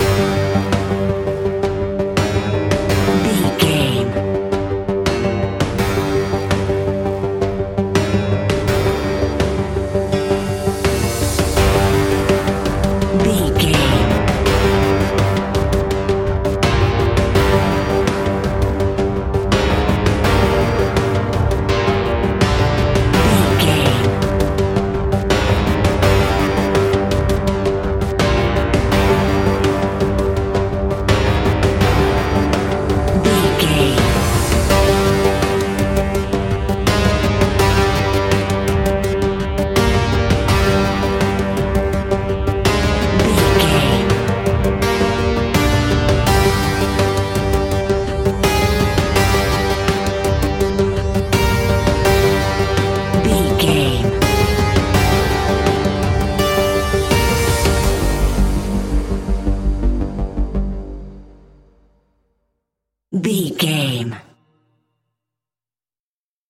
In-crescendo
Aeolian/Minor
scary
ominous
dark
haunting
eerie
ticking
electronic music
Horror Synths